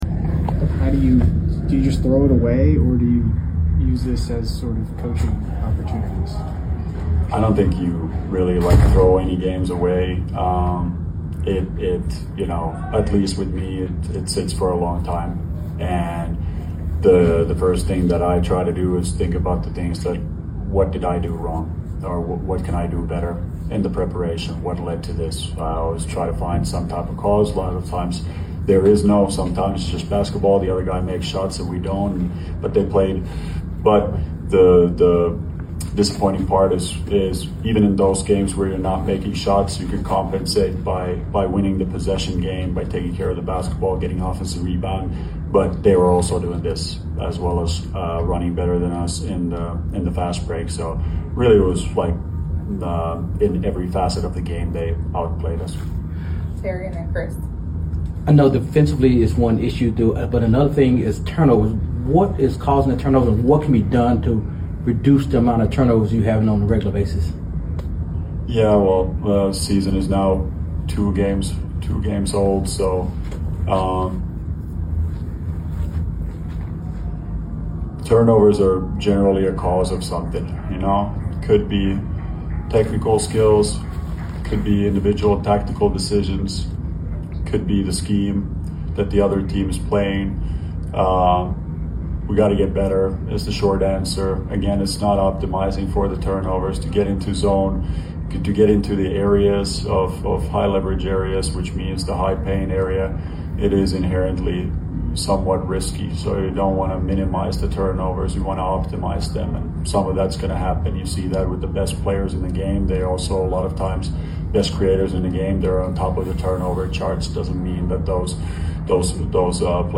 Memphis Grizzlies Coach Tuomas Iisalo Postgame Interview after losing to the Miami Heat at FedExForum.